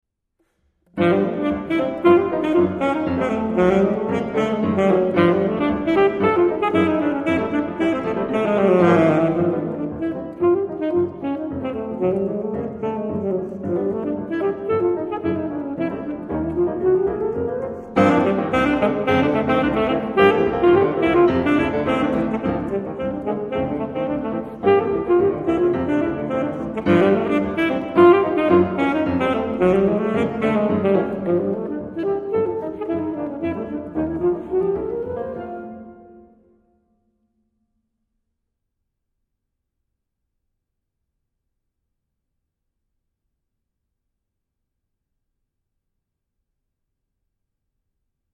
Merengue